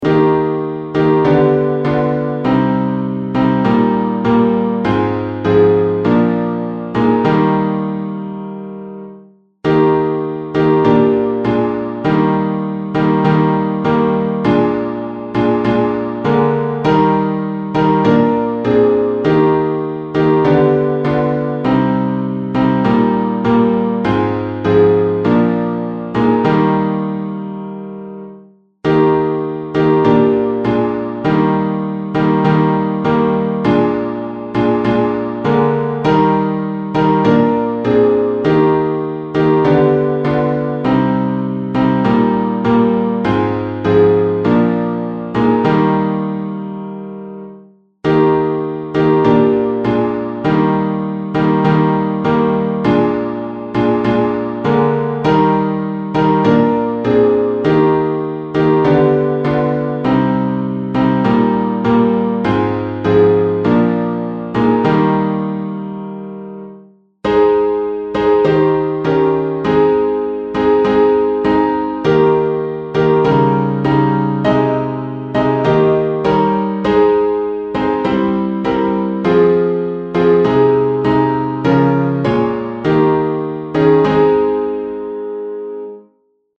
伴奏
四声